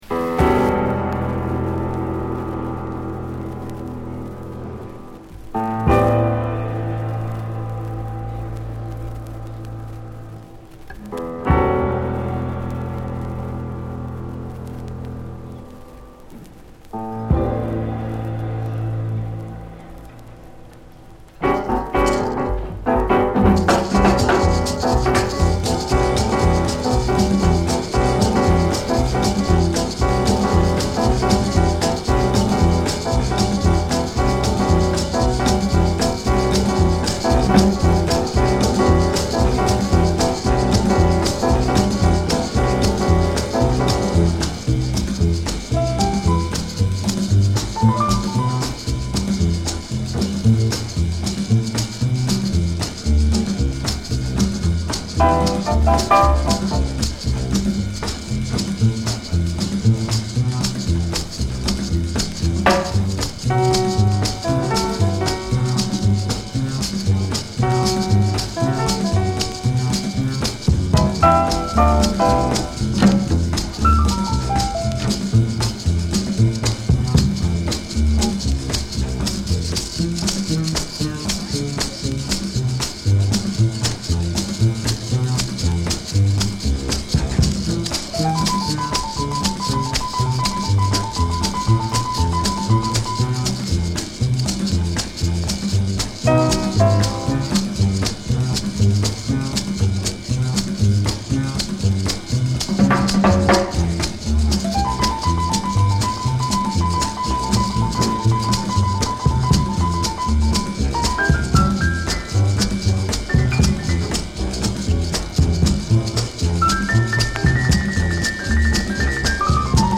ワシントンDCでのライブ録音となる本作。トリオ編成で小粋なライブを披露。
この時代からすでに確立されているピアノプレイは流石です。